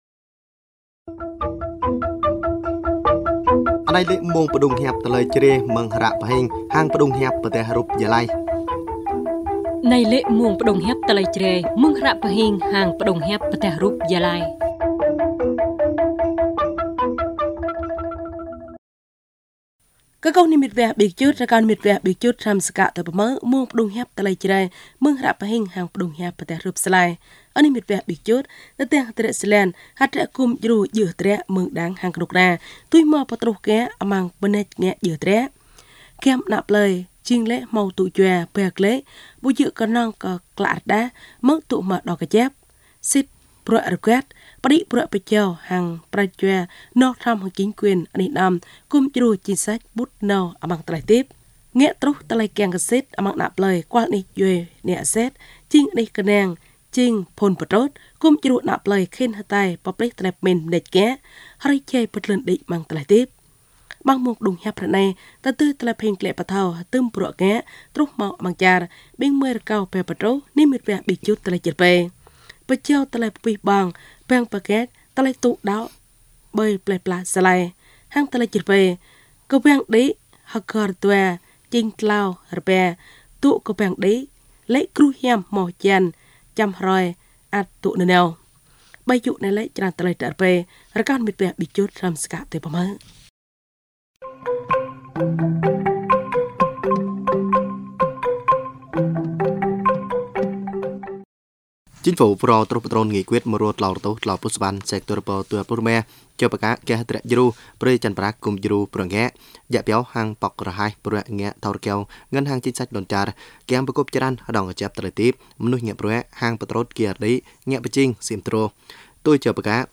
Thời sự PT tiếng Jrai